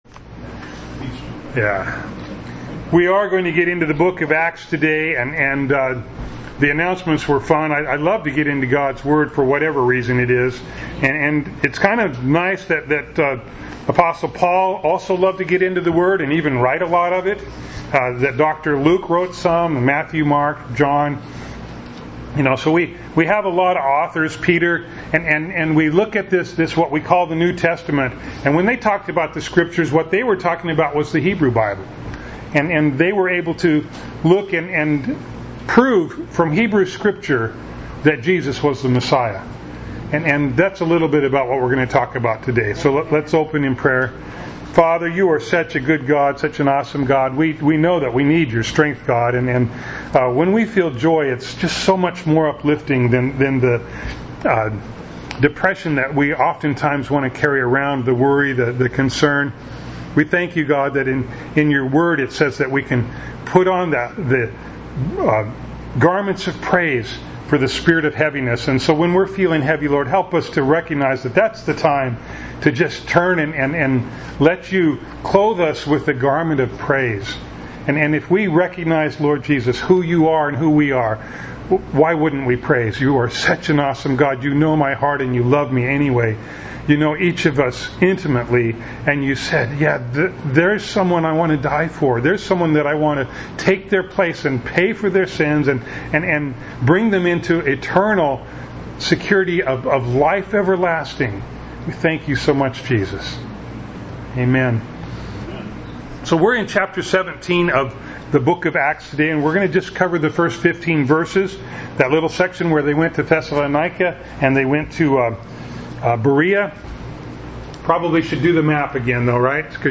Preacher
Acts 17:1-15 Service Type: Sunday Morning Bible Text